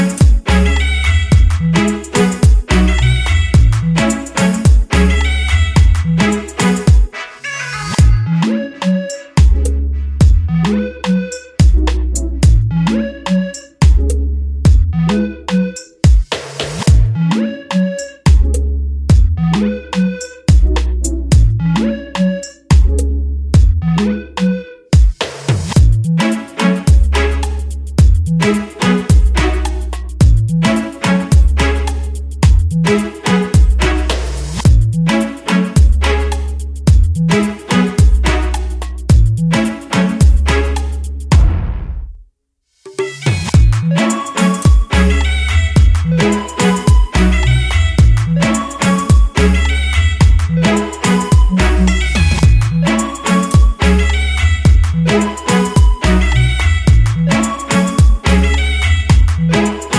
no Backing Vocals Dance 3:24 Buy £1.50